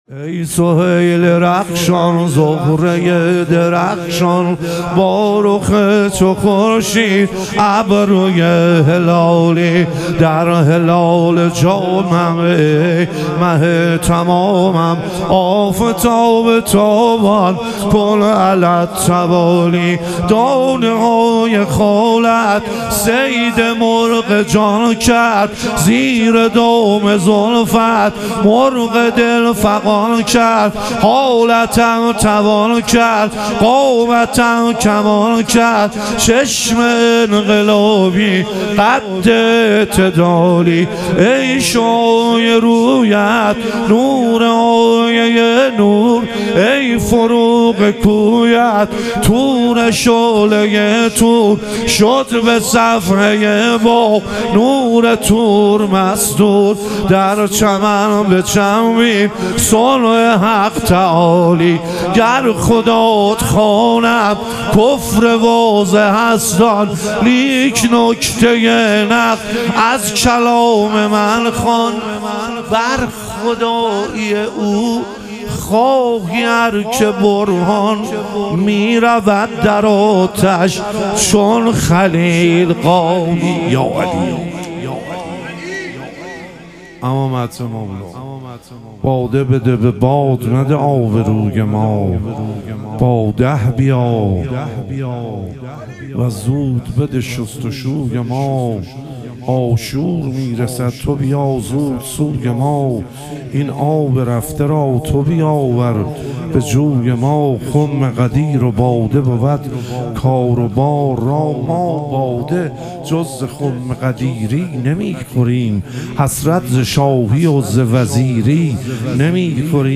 ظهور وجود مقدس حضرت علی اکبر علیه السلام - مدح و رجز